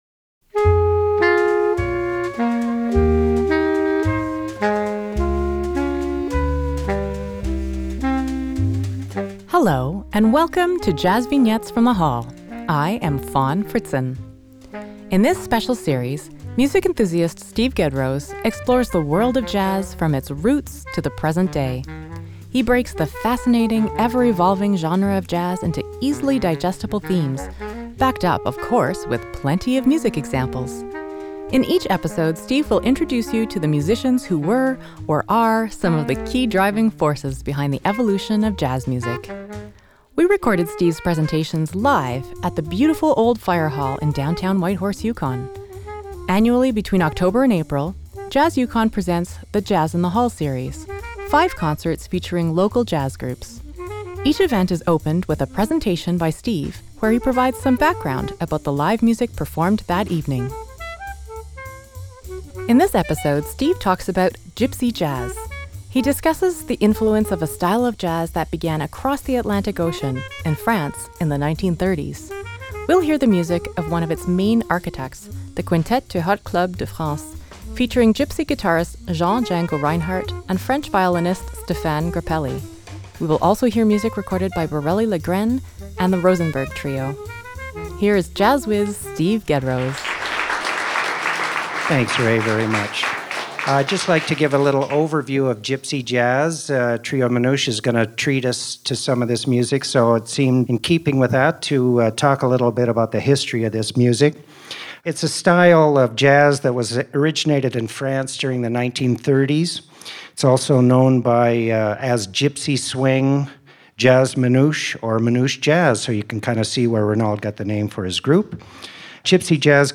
Jazz Vignettes from the Hall - Episode 05 - Gypsy Jazz